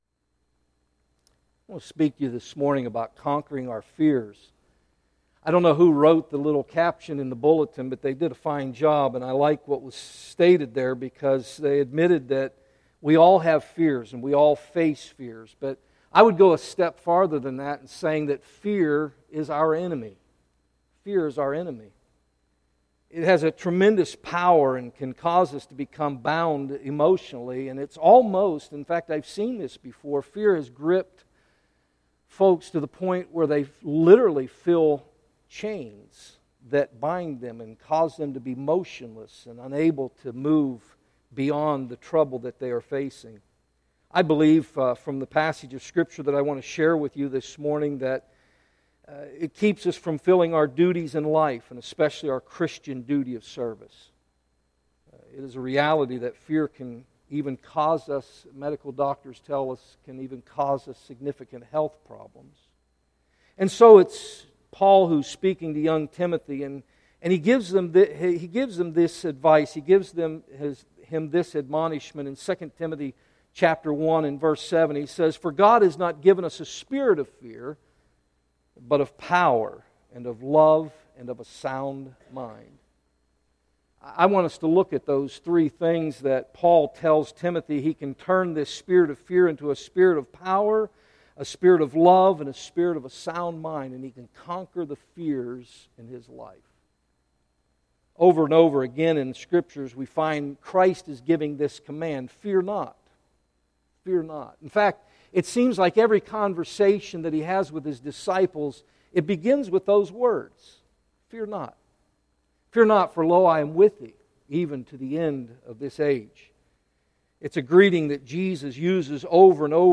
Messages by pulpit guests and special occasion sermons by pastor